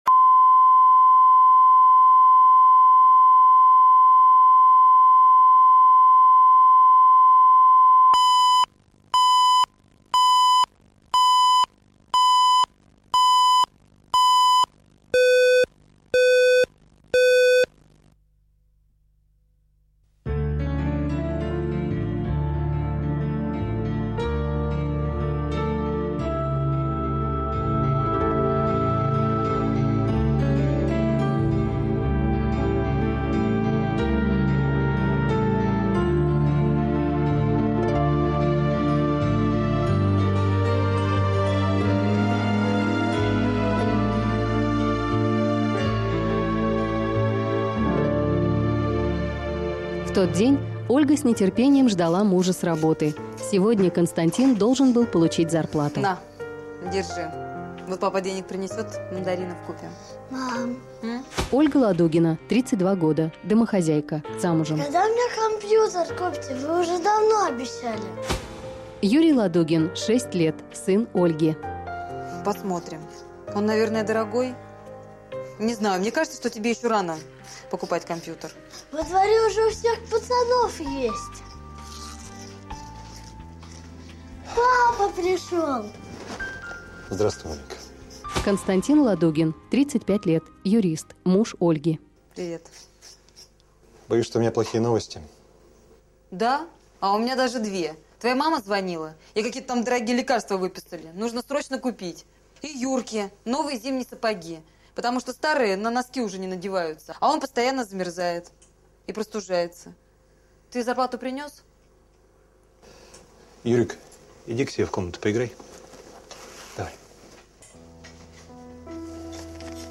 Аудиокнига Кто хозяин в доме | Библиотека аудиокниг